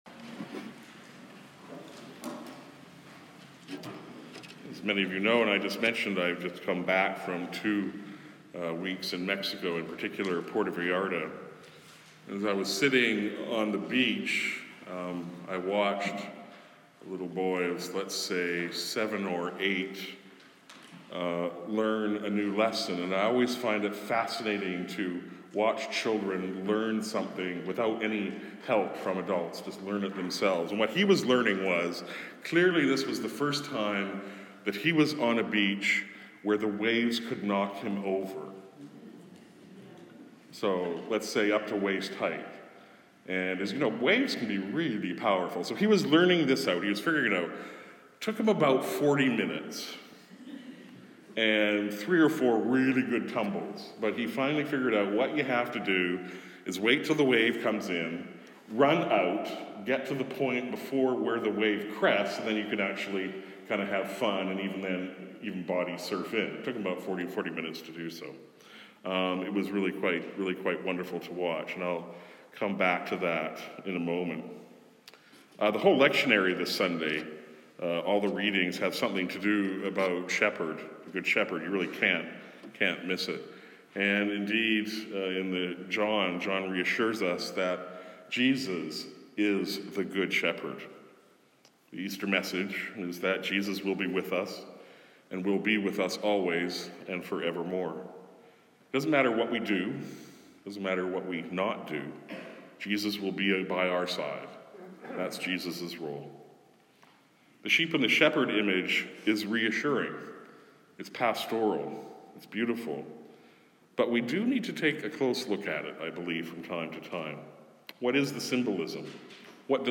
Sermons | St. John the Evangelist